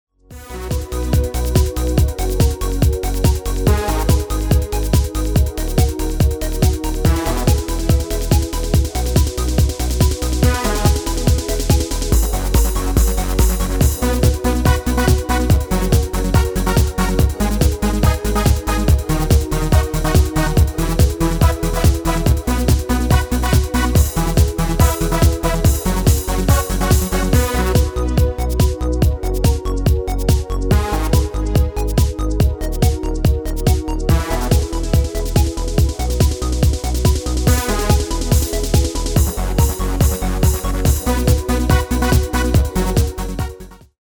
Demo/Koop midifile
Genre: Carnaval / Party / Apres Ski
Toonsoort: B/C
Demo's zijn eigen opnames van onze digitale arrangementen.